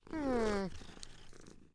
Npc Catpurr Sound Effect
Download a high-quality npc catpurr sound effect.
npc-catpurr-1.mp3